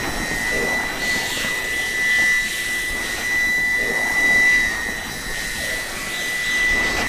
Screech.ogg